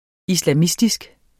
Udtale [ islaˈmisdisg ]